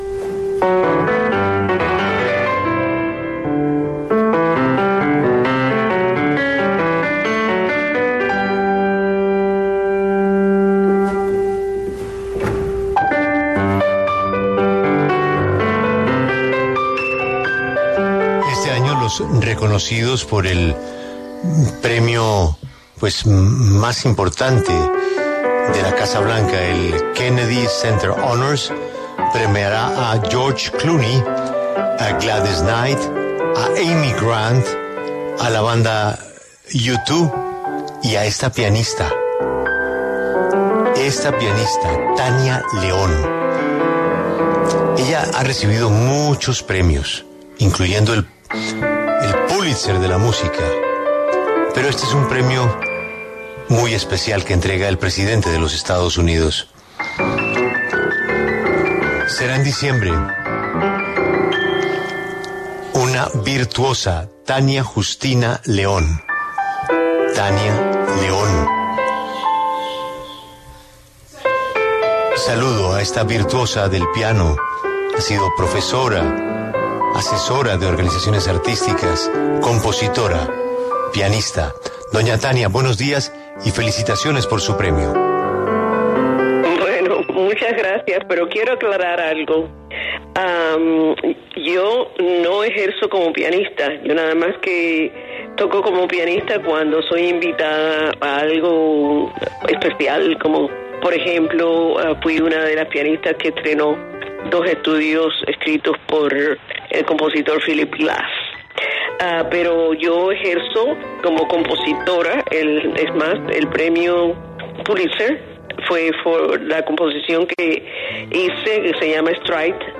Tania León, compositora estadounidense de origen cubano, habló en La W sobre el reconocimiento que recibirá en el Kennedy Center Honors 2022 en su edición número 45.